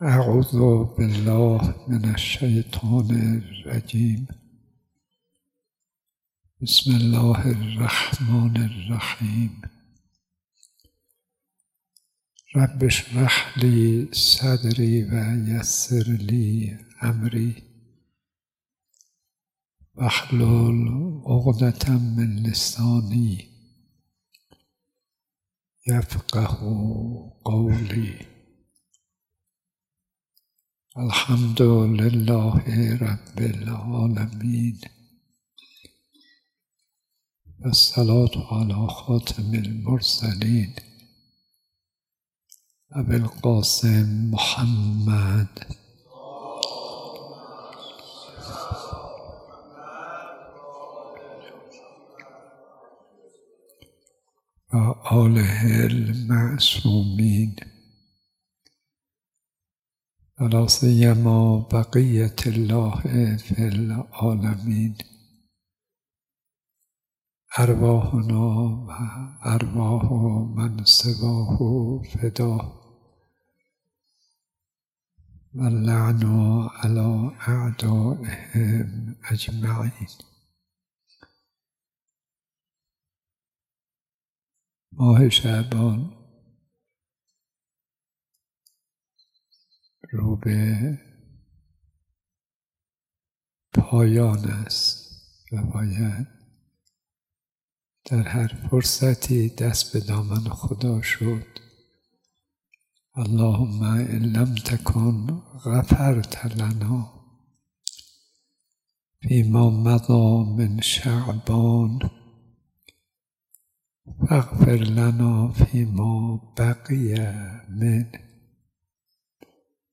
حضرت استاد > درس اخلاق حوزه علمیه امام خمینی (ره) تهران > سال 1403 در محضر استاد ۲۲۹ درس اخلاق آیت الله صدیقی؛ ۸ اردیبهشت ۱۴۰۳ در حال لود شدن فایل های صوتی...